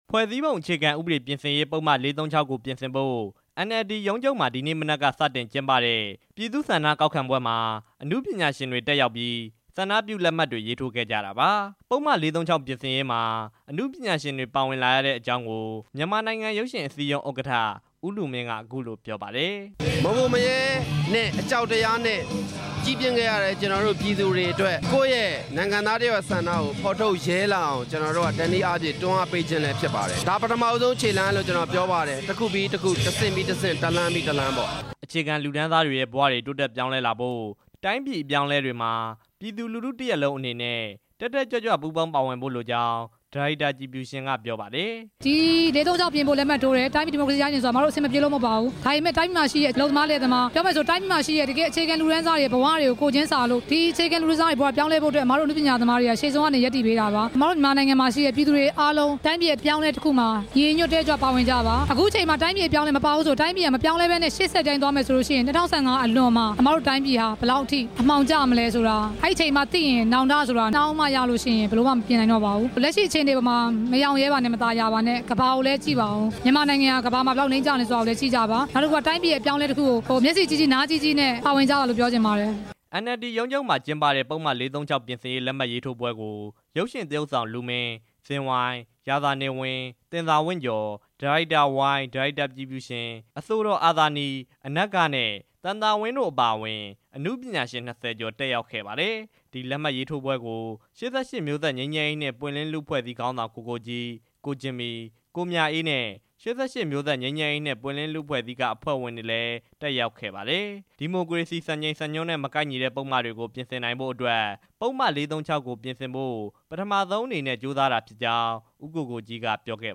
ဒီနေ့ လက်မှတ်ရေးထိုးခဲ့တဲ့ မြန်မာနိုင်ငံ ရုပ်ရှင်အစည်း အရုံး ဥက္ကဌ ကိုလူမင်းက သူတို့ပါဝင်လက်မှတ်ရေး ထိုးဖြစ်တဲ့အကြောင်း အခုလို ပြောပါတယ်။
RFA သတင်းထောက်